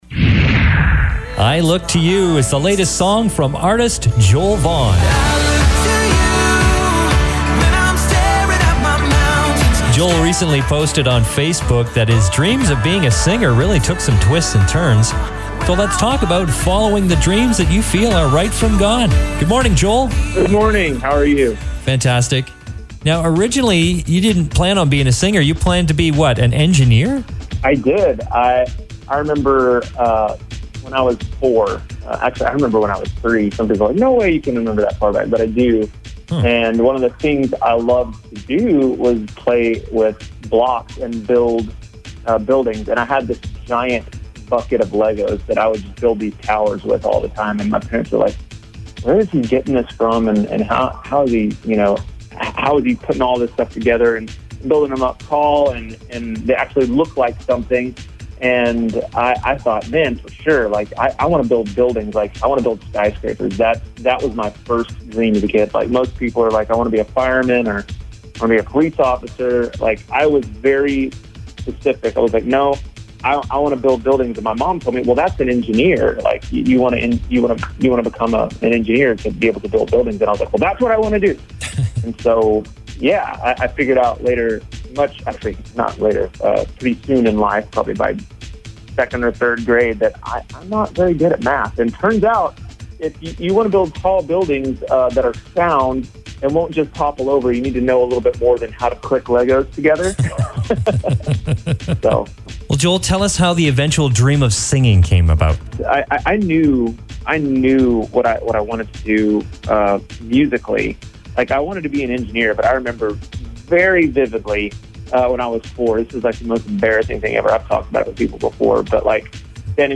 Interview - Life 100.3